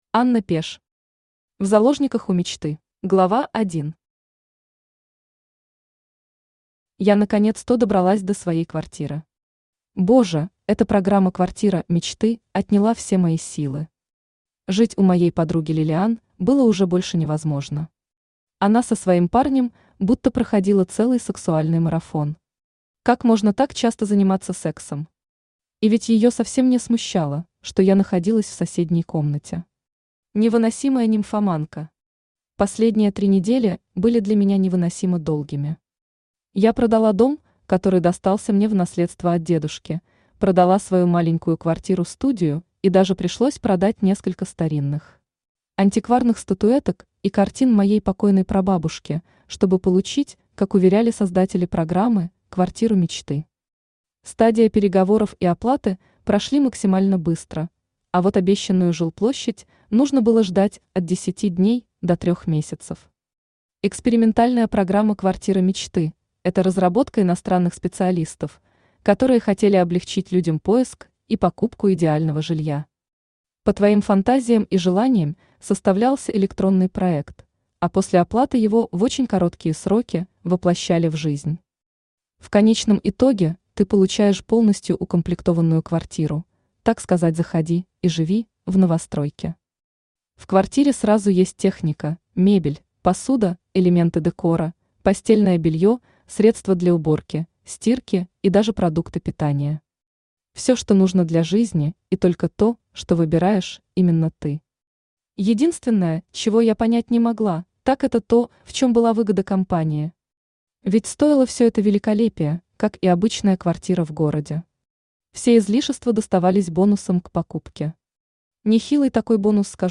Аудиокнига В заложниках у мечты | Библиотека аудиокниг
Aудиокнига В заложниках у мечты Автор Анна Пеш Читает аудиокнигу Авточтец ЛитРес.